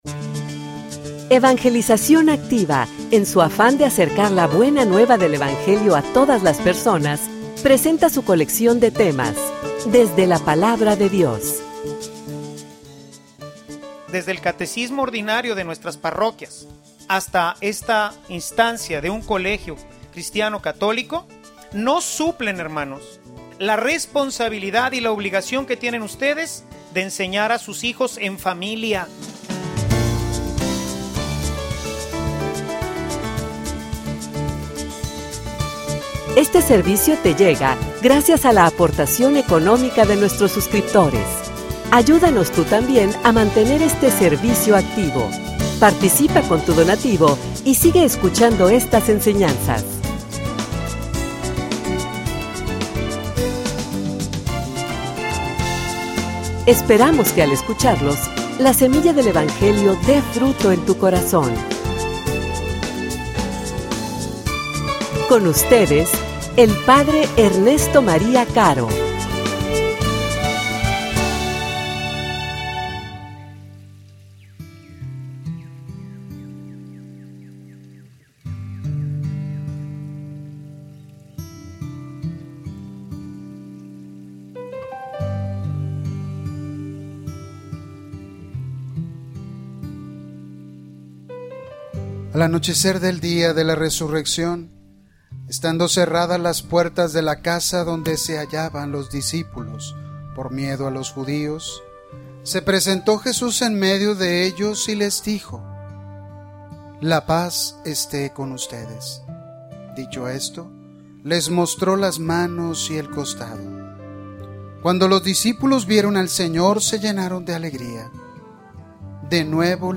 homilia_Perseverantes.mp3